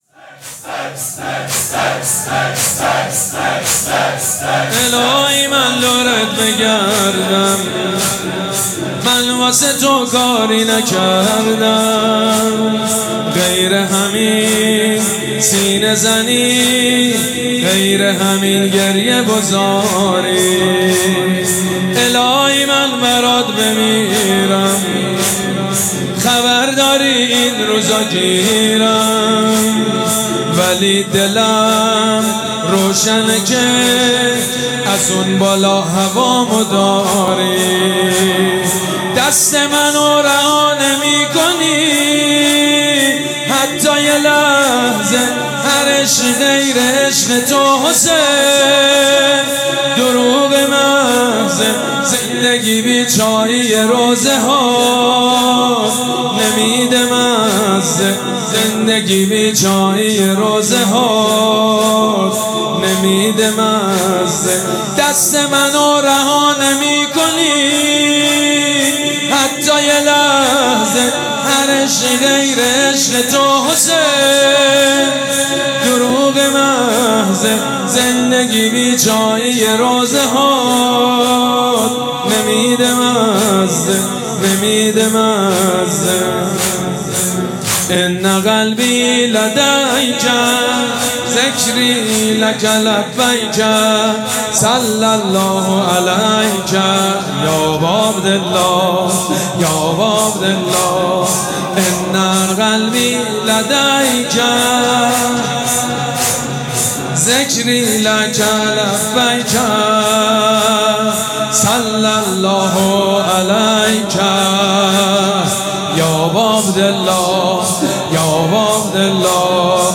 مراسم عزاداری شب دهم محرم الحرام ۱۴۴۷
مداح
حاج سید مجید بنی فاطمه